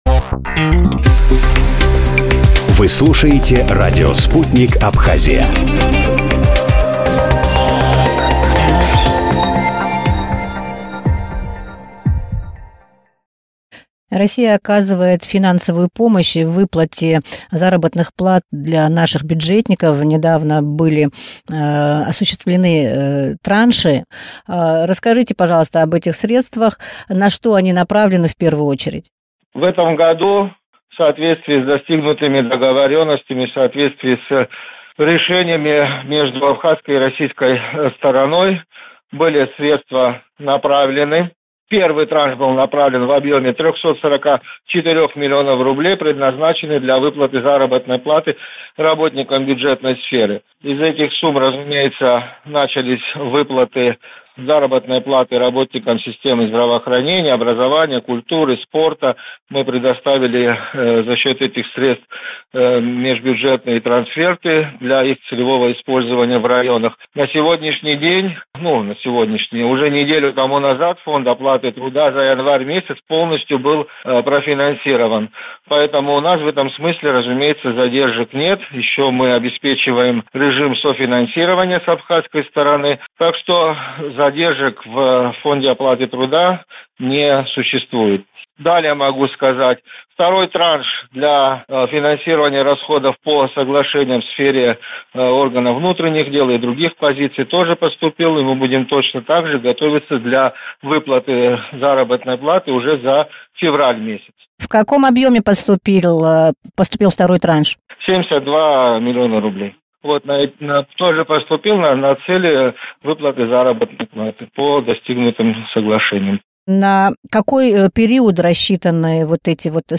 И. о. министра финансов Абхазии Владимир Делба в интервью радио Sputnik рассказал о поступлении российской финпомощи на выплаты зарплат бюджетникам.